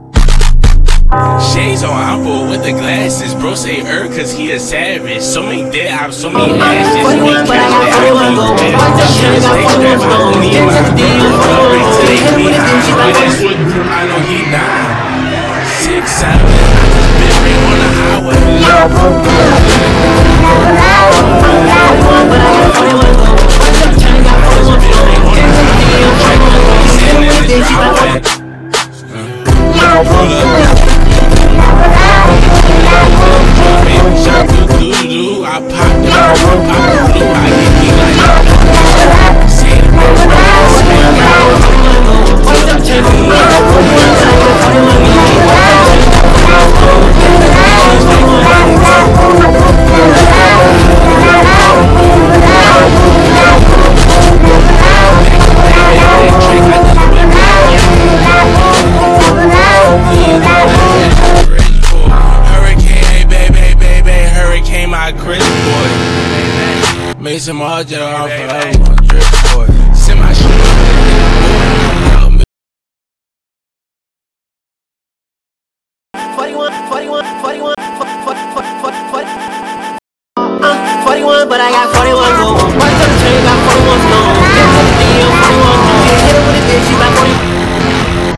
labubu_67_41_funk_tuff_sigma_remix.mp3